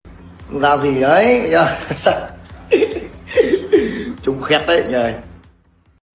Thể loại: Câu nói Viral Việt Nam
Nó mang tính hài hước và được cộng đồng mạng yêu thích, thường xuất hiện trong các video vui nhộn hoặc tình huống giải trí.